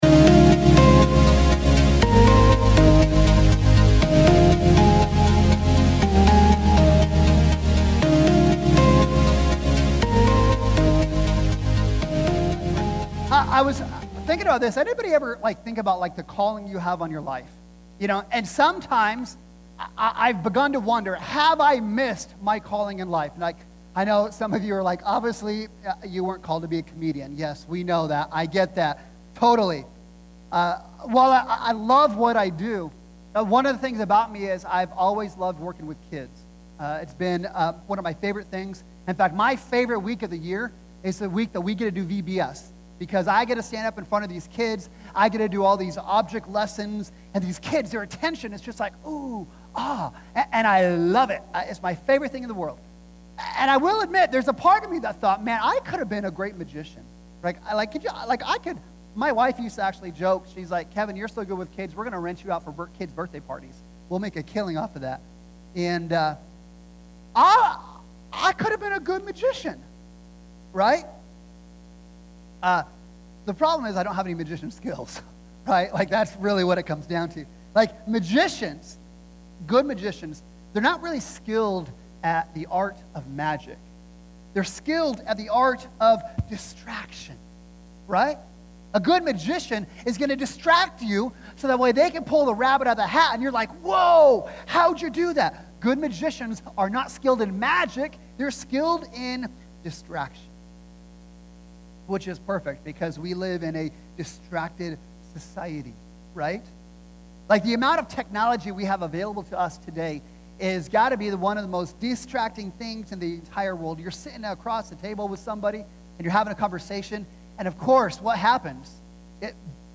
Weekly sermons from Restoration Church of Yakima